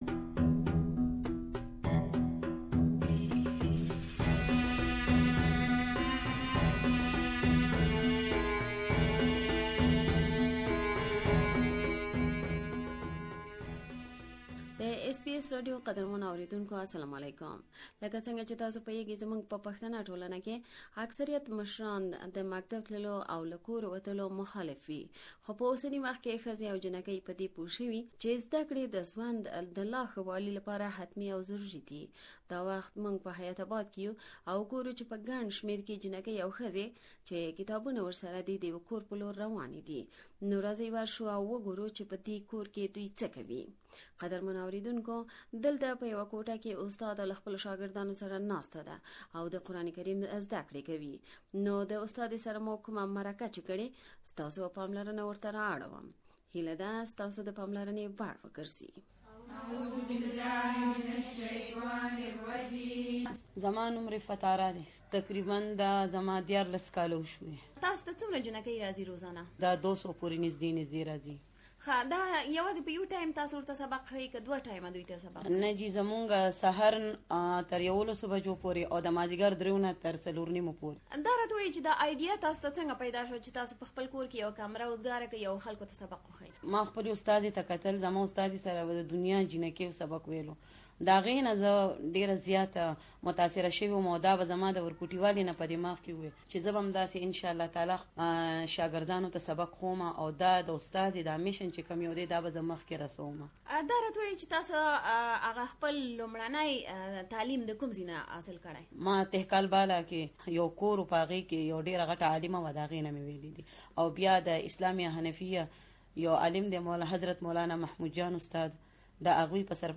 We have interviewed the president of this center and you can listen to her full interview here.